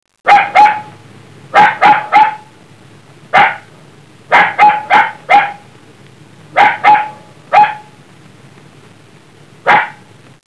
Puppy1
PUPPY1.wav